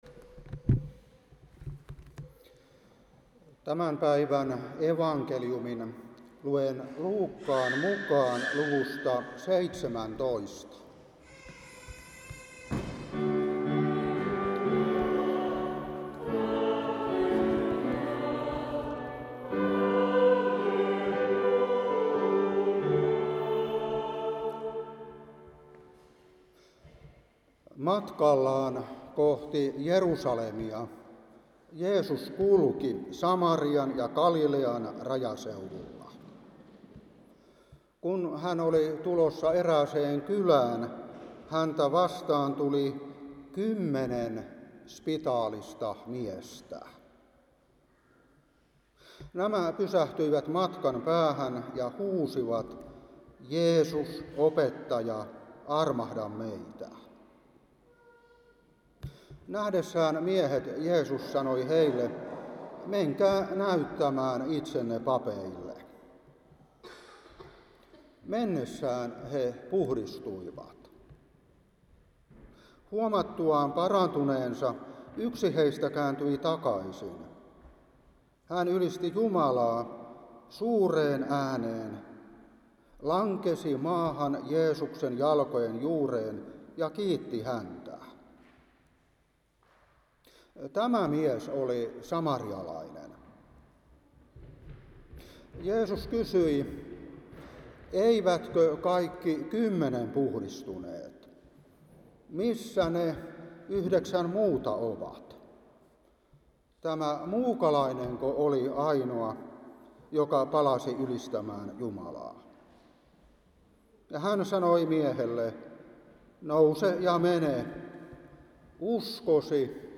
Saarna 2025-9.
Veteli